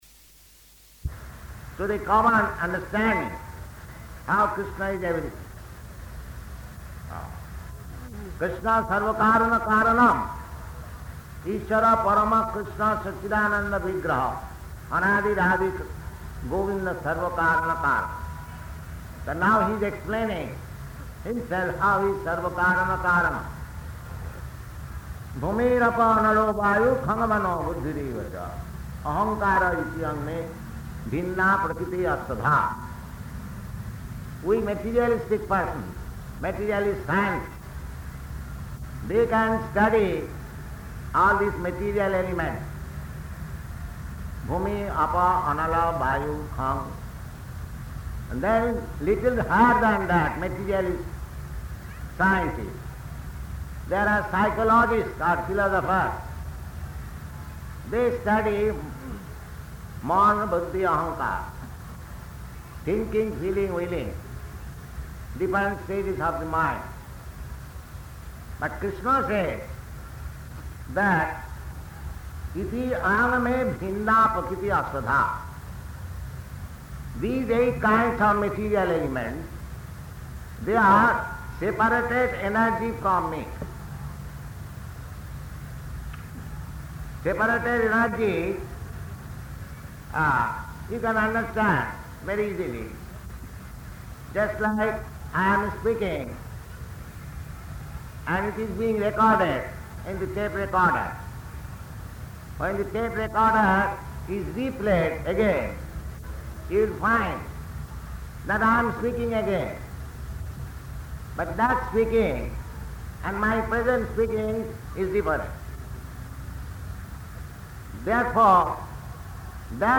Bhagavad-gītā 7.4–5 --:-- --:-- Type: Bhagavad-gita Dated: March 30th 1971 Location: Bombay Audio file: 710330BG-BOMBAY.mp3 Prabhupāda: ...to the common understanding how Kṛṣṇa is everything.